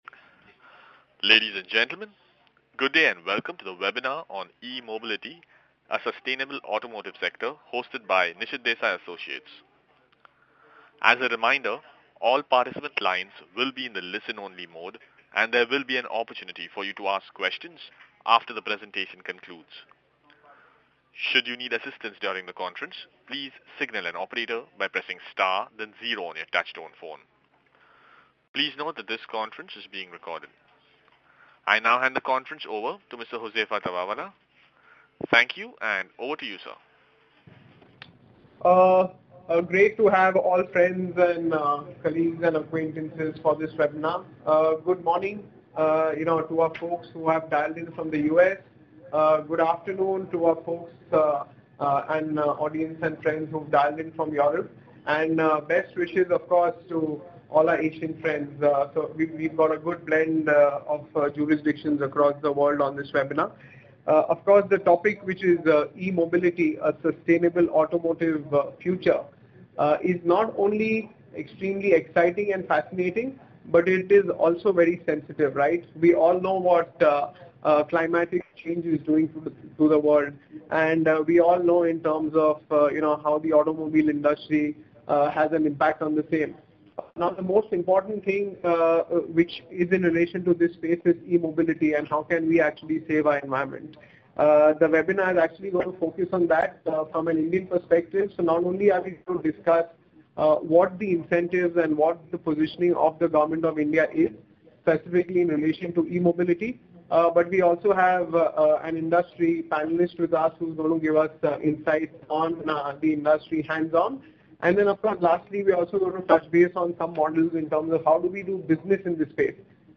Webinar: E-Mobility : A sustainable automotive sector